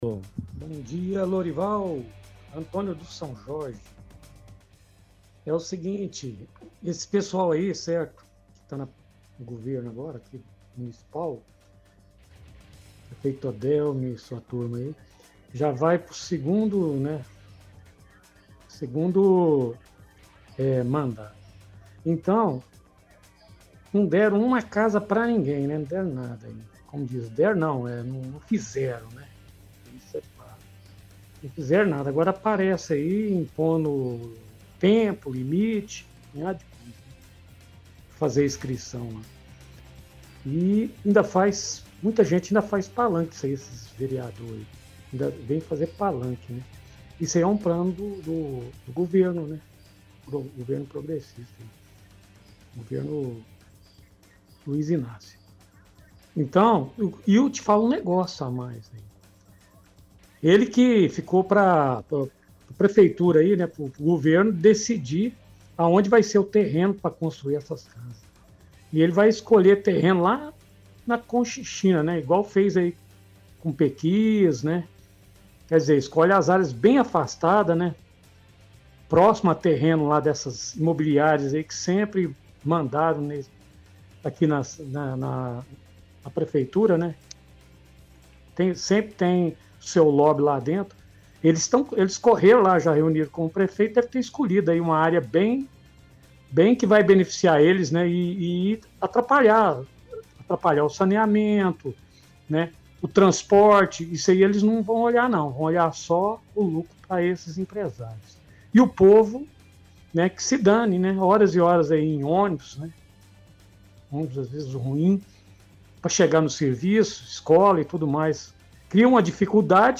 – Ouvinte reclama que gestão do prefeito Odelmo não fizeram nenhuma casa para a população.